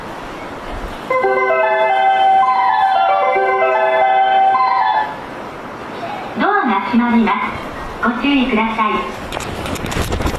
接近放送 ここも自動放送が簡易になっています。